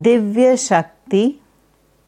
Sanskrit Divyashakti korrekte Aussprache anhören
Dann sieht das so aus: दिव्यशक्ति, in der wissenschaftlichen IAST Transliteration divya-śakti. Hier kannst du hören, wie man ganz korrekt das Wort Divyashakti ausspricht.